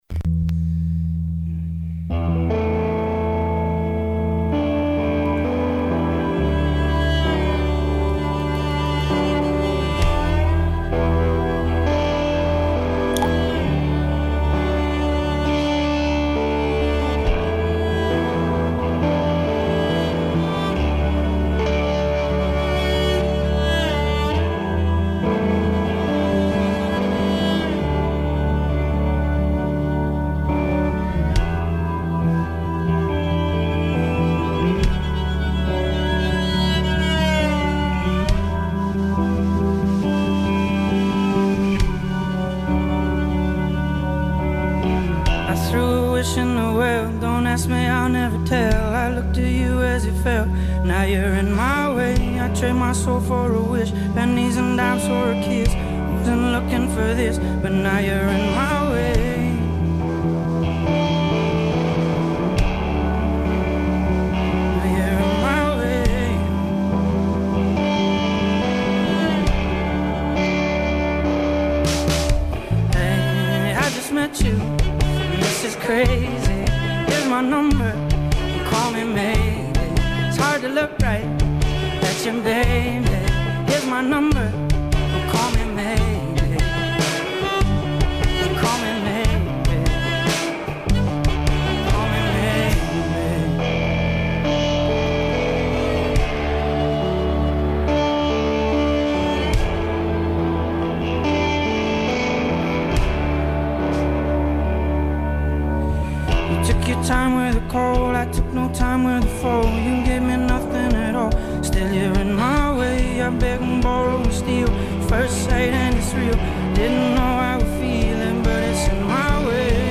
Same words, same basic arrangement, different energy.